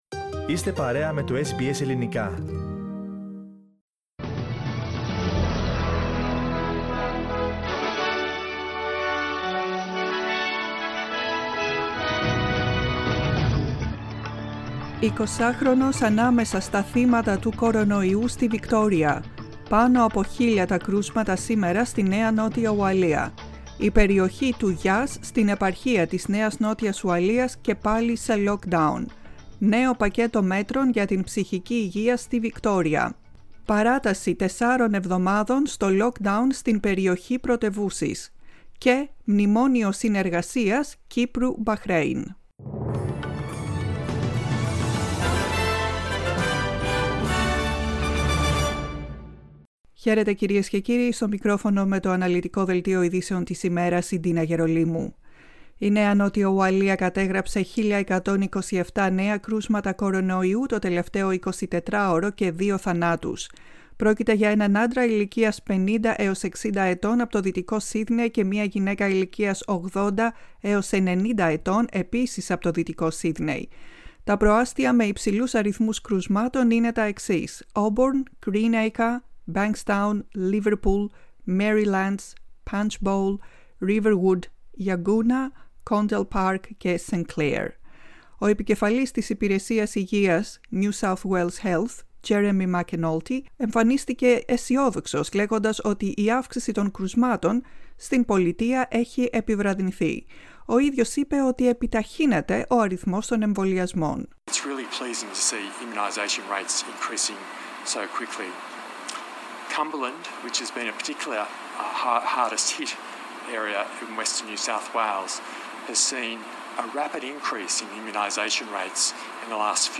The main bulletin of the day from the Greek Language Program.